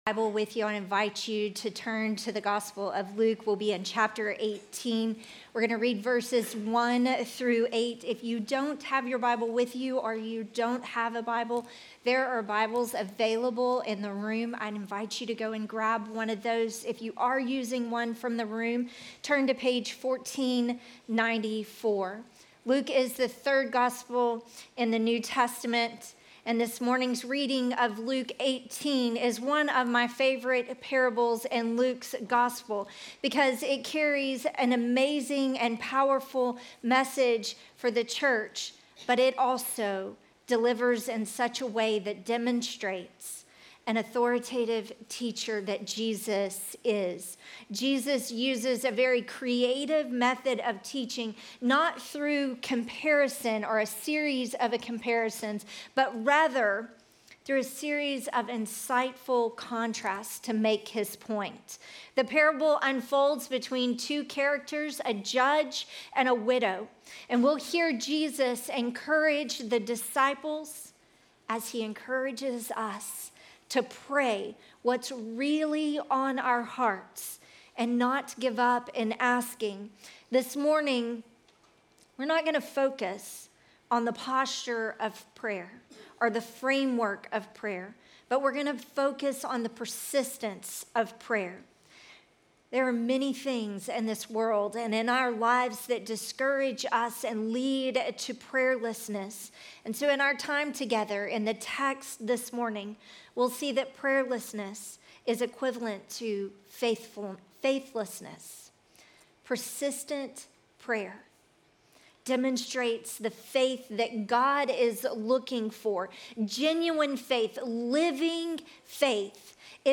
Sermon text: Luke 18:1-8